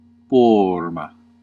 Ääntäminen
US : IPA : [ˈfɪɡ.jɚ]